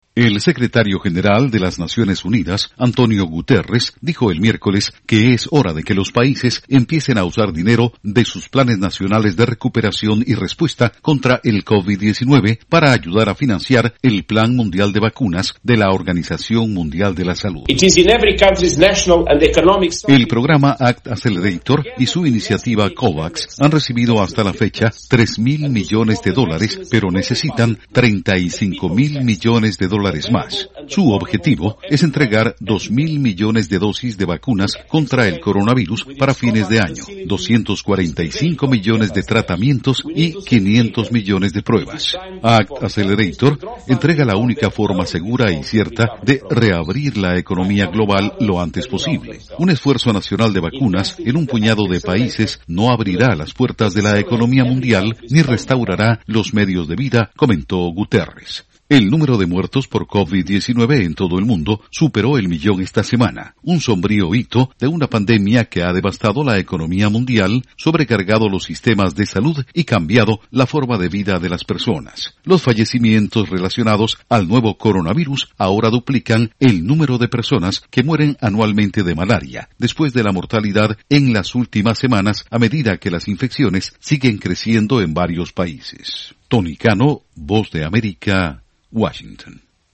Es hora de financiar esfuerzo mundial para vacuna COVID-19 con dinero de planes nacionales advierte Naciones Unidas. Informa desde la Voz de América